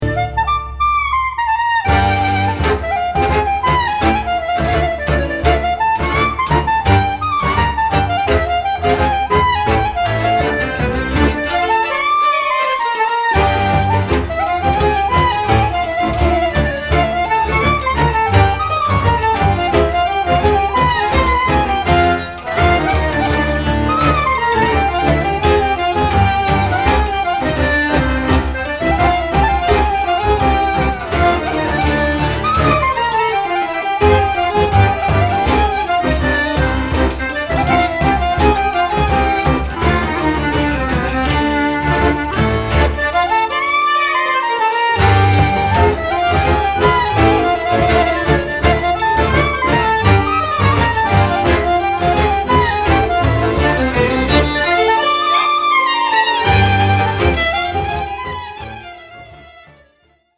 Genres: Klezmer, Jazz, World.
clarinet
percussion
is quite danceable.